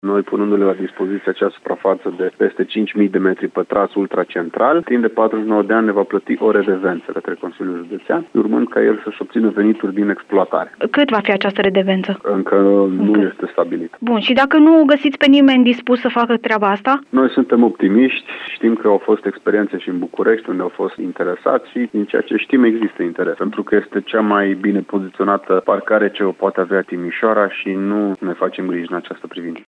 Deocamdată, administrația județeană nu a stabilit cuantumul redevenței pe care o va plăti operatorul, mai spune Alexandru Proteasa.
Alexandru-Proteasa-2.mp3